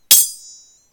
Other Sound Effects
sword_clash.3.ogg